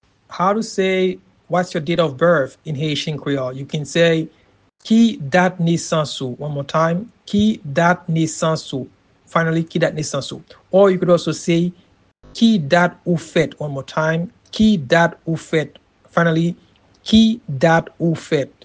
Pronunciation and Transcript: